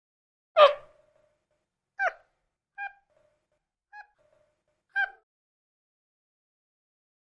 Monkey, Capuchin Calls. Four Quiet Low Pitched Hoots. Close Perspective.